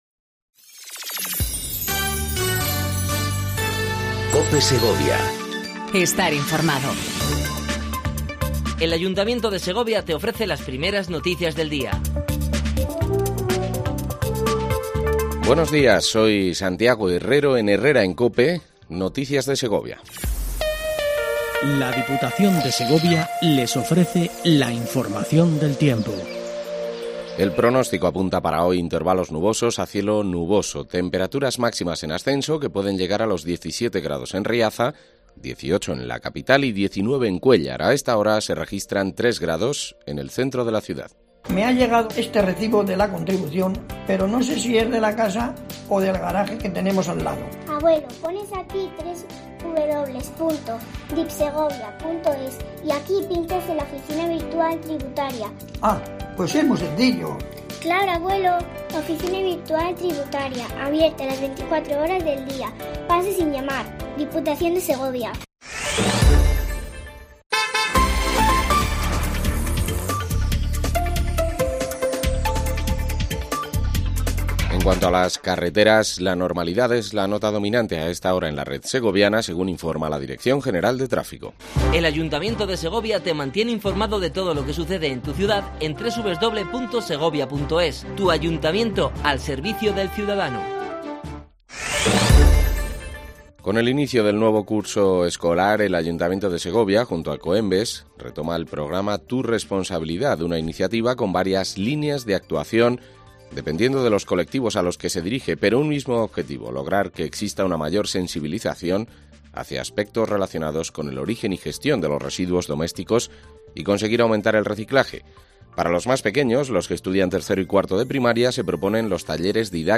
AUDIO: Primer informativo local en cope segovia